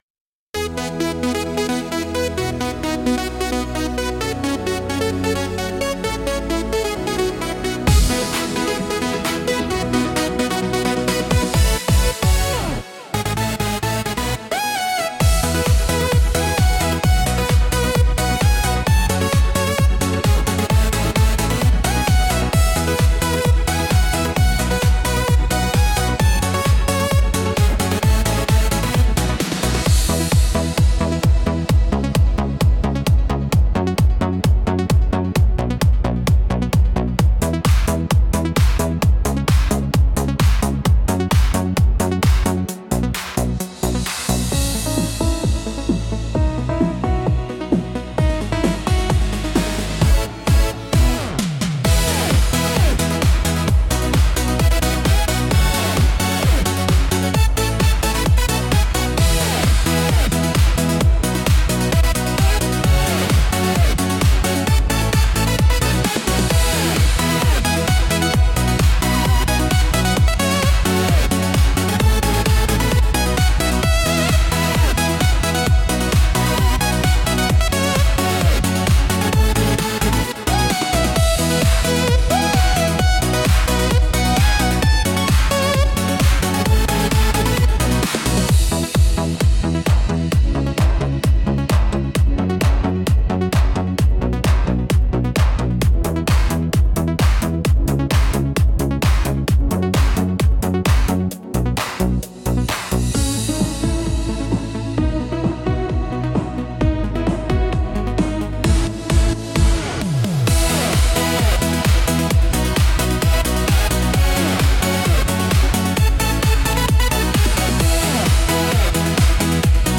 Instrumental - Aerobics Jetstream 2.38